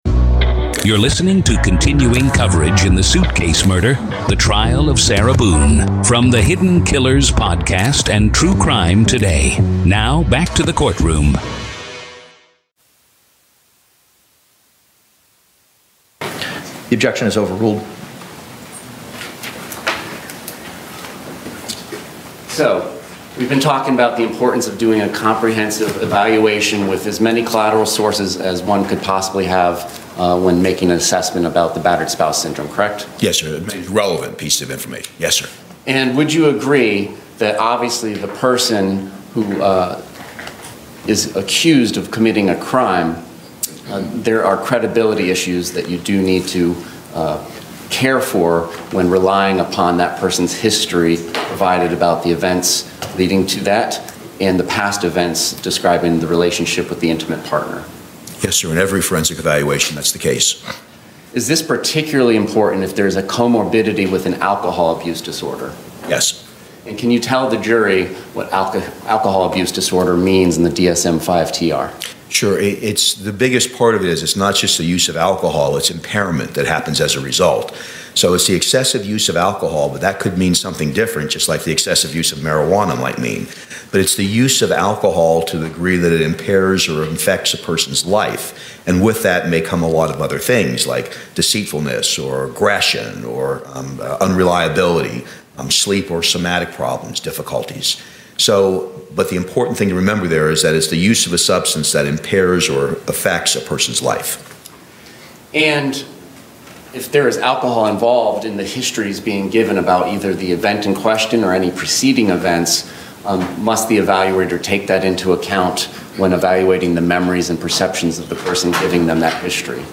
RAW COURT AUDIO